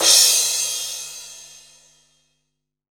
Index of /90_sSampleCDs/300 Drum Machines/Akai MPC-500/1. Kits/Fusion Kit
F Stand Crash 1.WAV